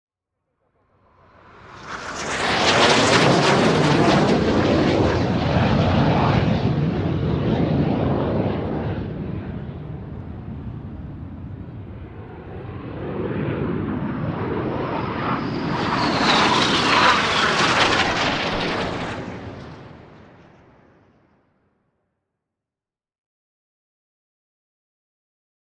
Airplane Avro Vulcan overhead jet